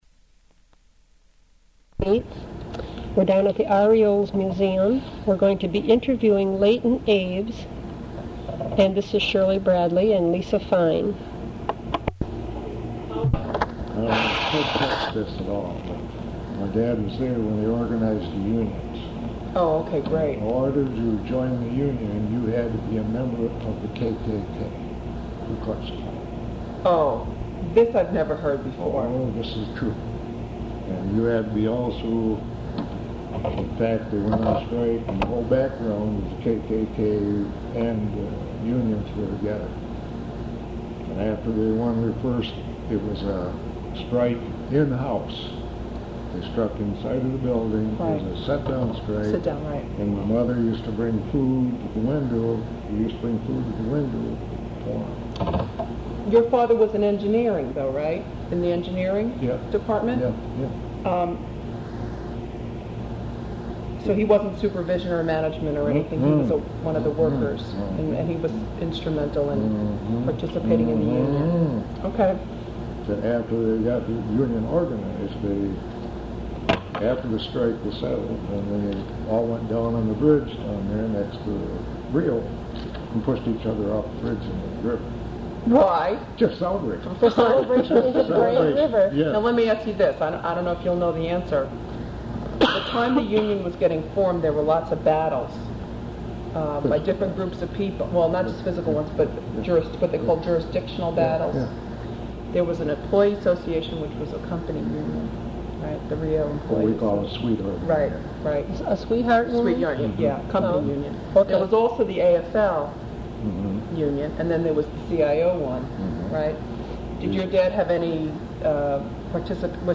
Recorded as part of the REO Memories oral history project.